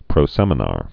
(prō-sĕmə-när)